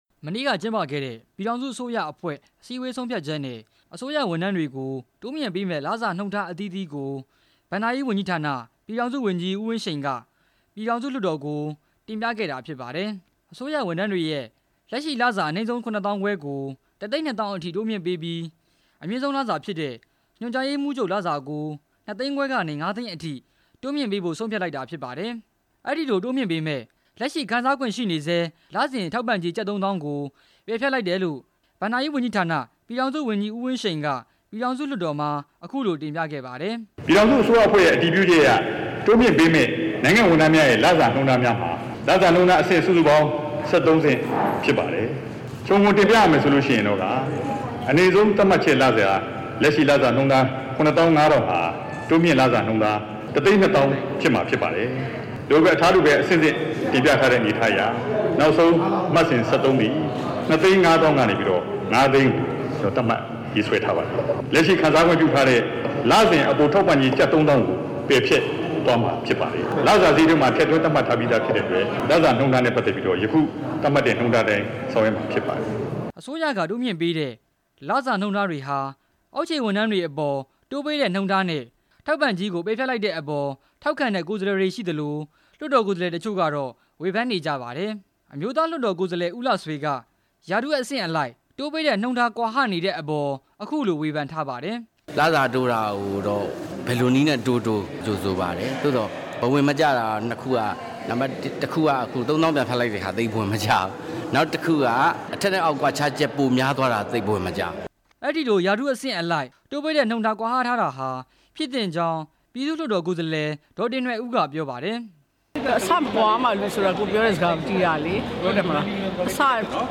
အစိုးရဝန်ထမ်းတွေရဲ့ လစာငွေတိုးမြှင့်ပေးလိုက်တဲ့အပေါ် တင်ပြချက်